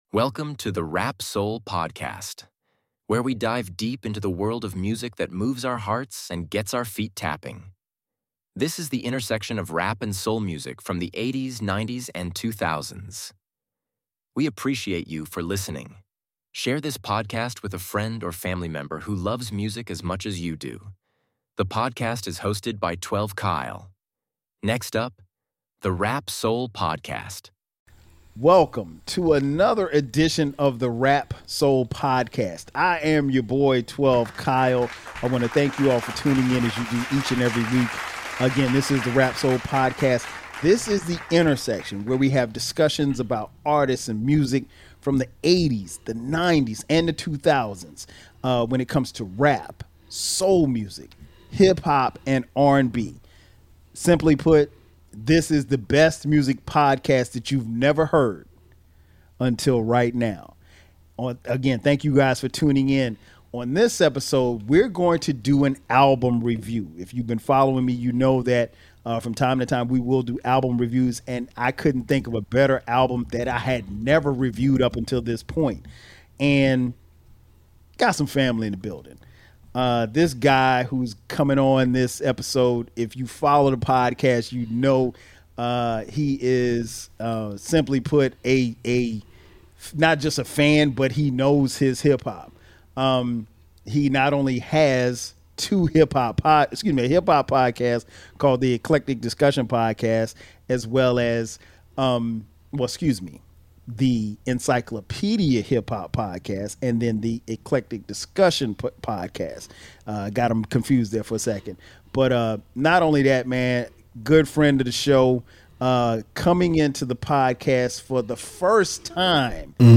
They explore the songs while also reflecting on the album's impact on hip hop culture. The discussion culminates in a debate over the album's rating, highlighting its timeless quality and relevance in today's music landscape.